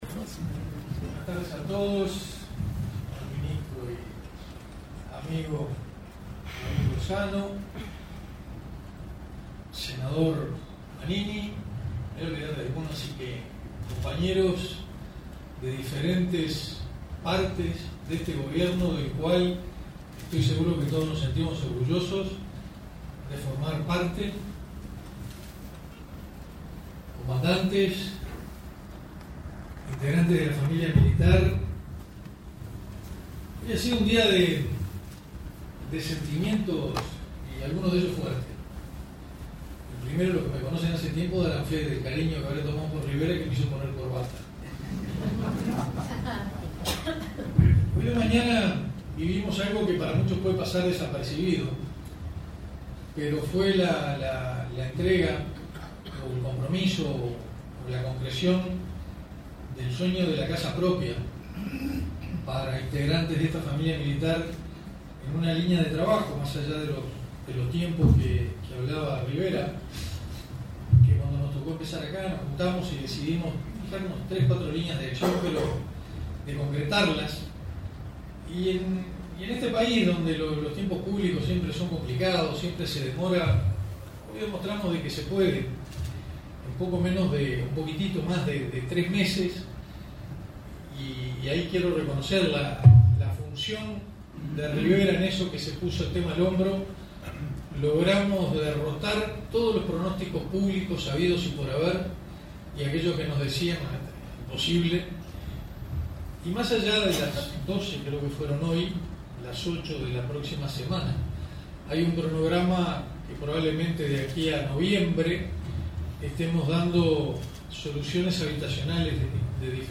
Palabras del ministro de Defensa Nacional, Amando Castaingdebat